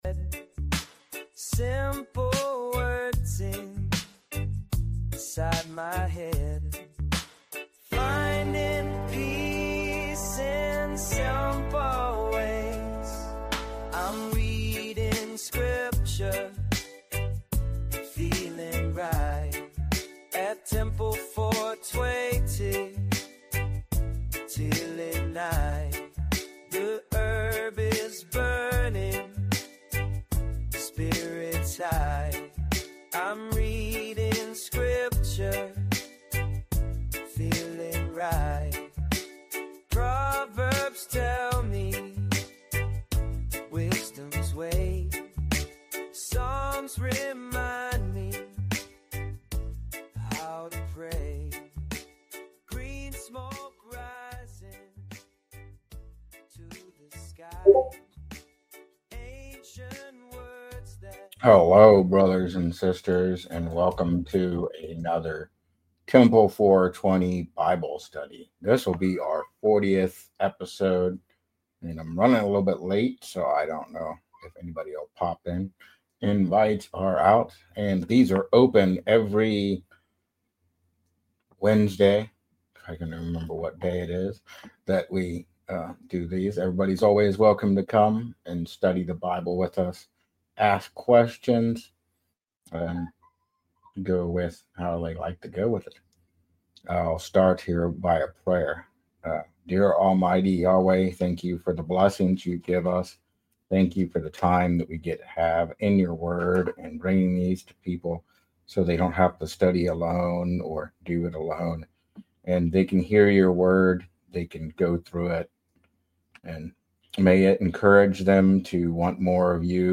Temple 420 Bible Study sound effects free download